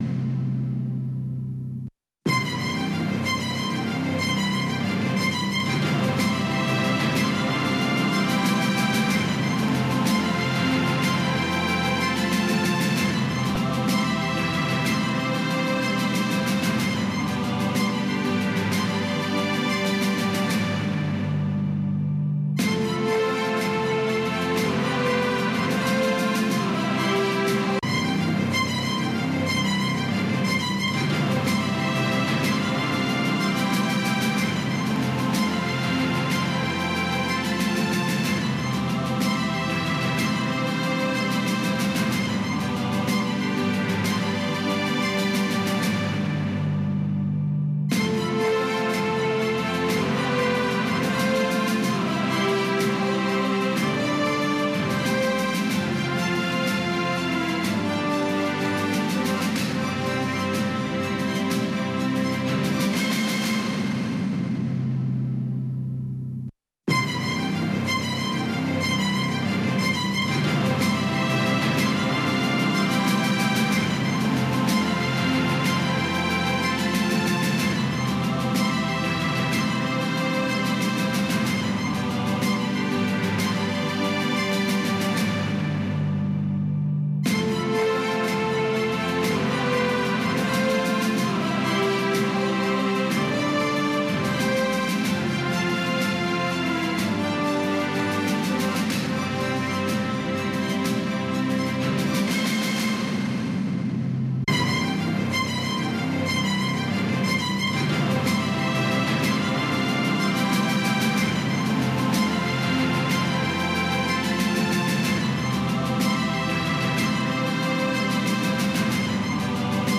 خبرونه
د وی او اې ډيوه راډيو خبرونه چالان کړئ اؤ د ورځې د مهمو تازه خبرونو سرليکونه واورئ. په دغه خبرونو کې د نړيوالو، سيمه ايزو اؤمقامى خبرونو هغه مهم اړخونه چې سيمې اؤ پښتنې ټولنې پورې اړه لري شامل دي.